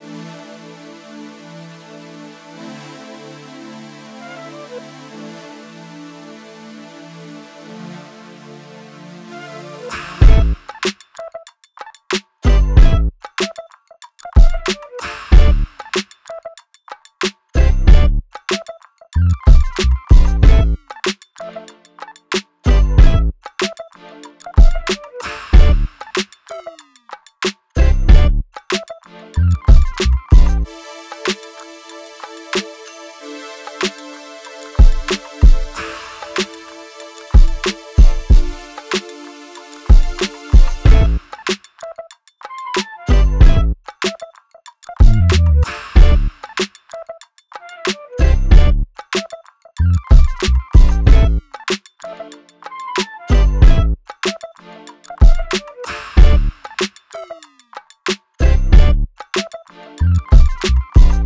Am 94